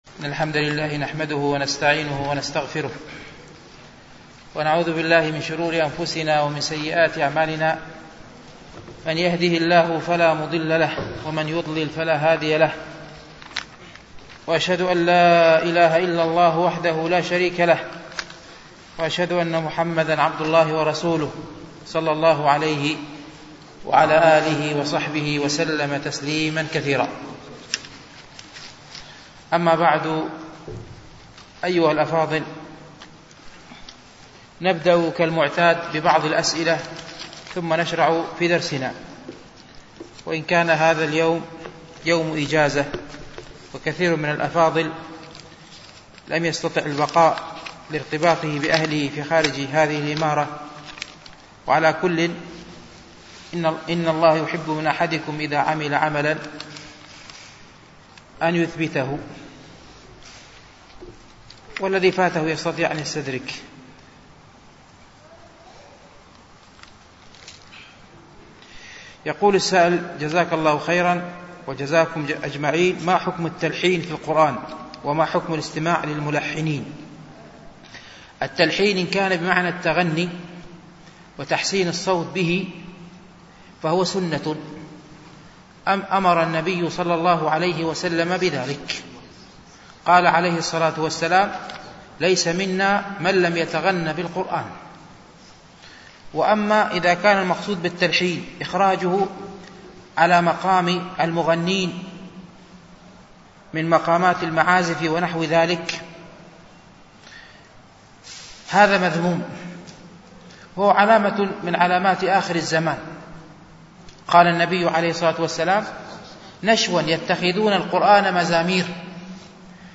شرح رياض الصالحين ـ الدرس الثامن والتسعون